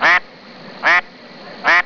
Duck Quack 5